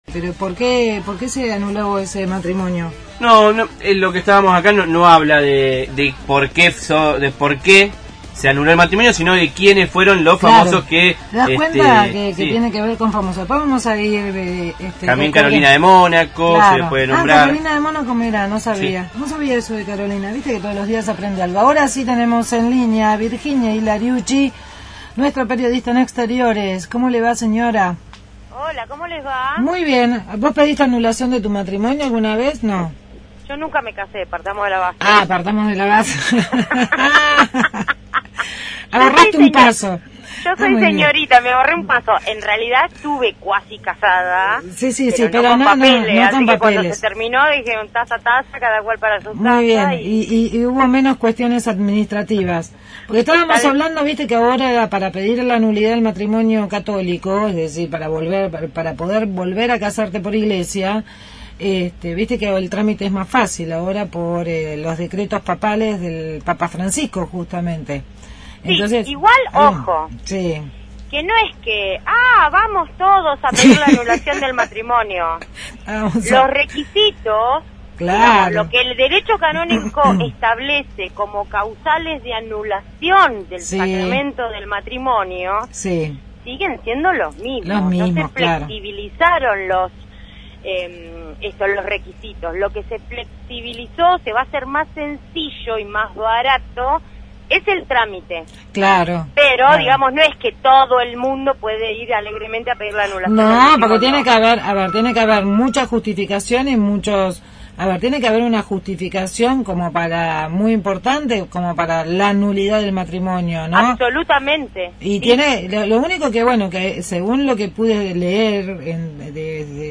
Móvil/ Colectivos que reemplazarán al Roca – Radio Universidad
Tema: desde la terminal de trenes con el servicio de colectivos que reemplazará al ferroviario mientras se llevan adelante los trabajos de electrificación del ramal La Plata – Constitución durante los próximos 90 días.